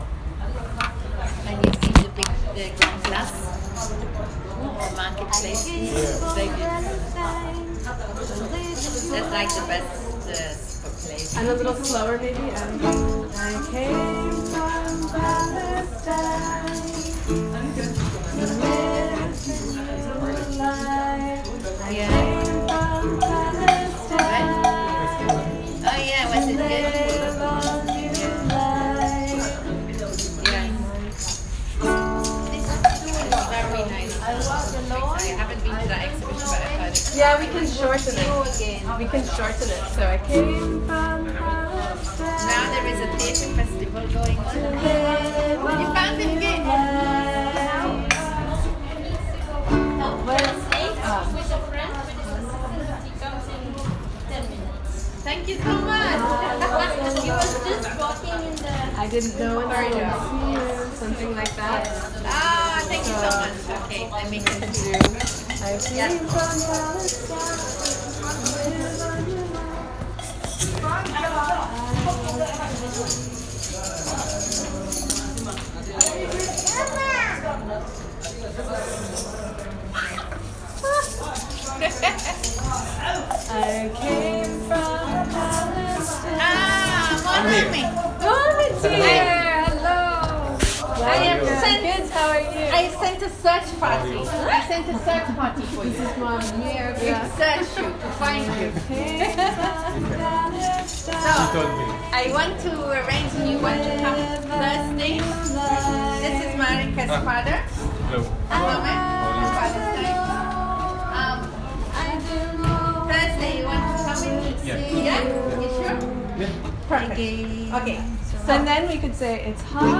These are recordings from the center that show the process of writing the song:
the-sun-will-come-again-writing-the-song02.m4a